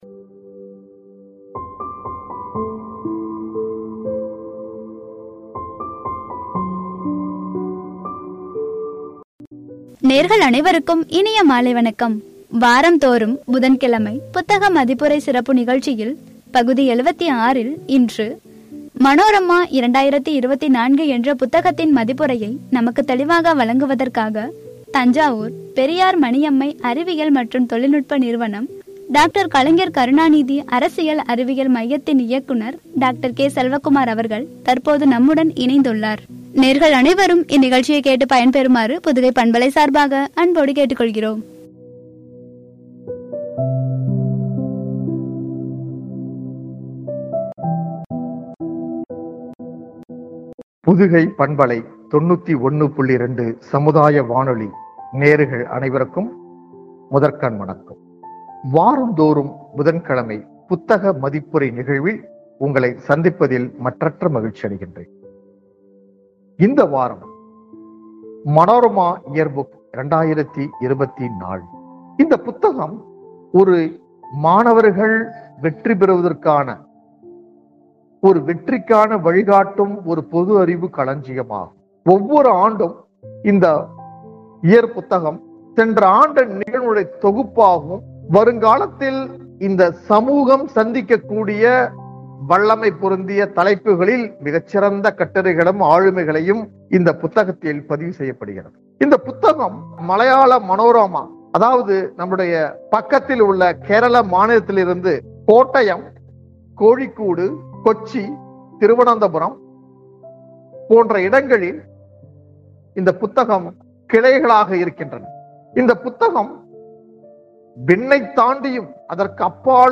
“மனோரமா 2024” புத்தக மதிப்புரை (பகுதி –76), என்ற தலைப்பில் வழங்கிய உரை.